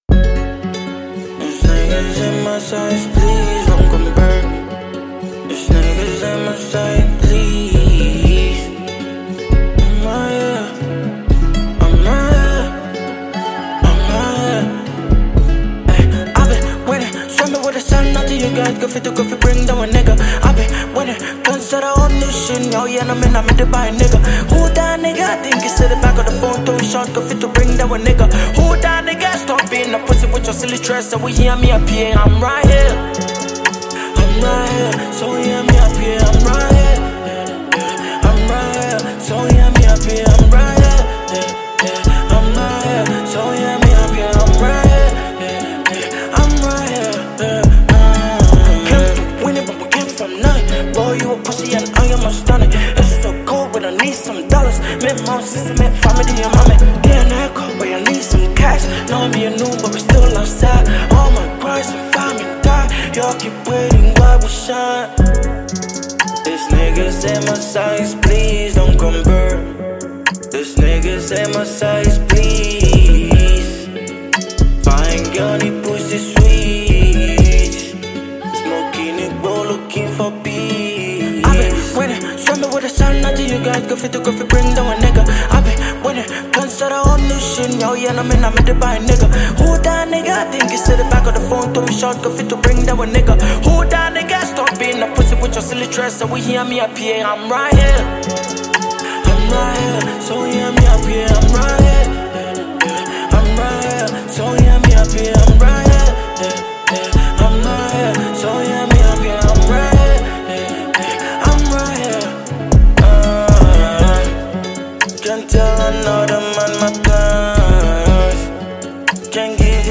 Ghanaian award winning rapper